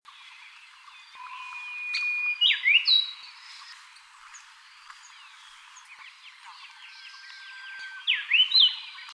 物種名稱 強腳樹鶯 Cettia fortipes robusticeps
錄音地點 高雄市 桃源區 梅山
行為描述 鳴唱 錄音器材 錄音: 廠牌 Denon Portable IC Recorder 型號 DN-F20R 收音: 廠牌 Sennheiser 型號 ME 67